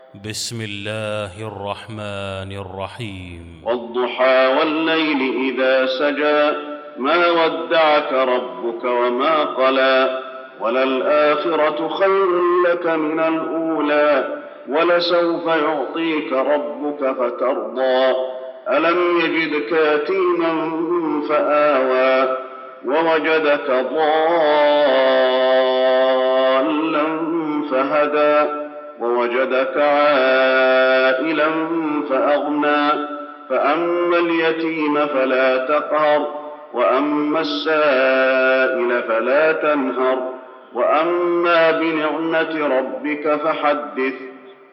المكان: المسجد النبوي الضحى